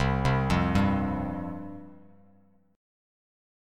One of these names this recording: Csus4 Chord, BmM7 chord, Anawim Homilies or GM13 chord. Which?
Csus4 Chord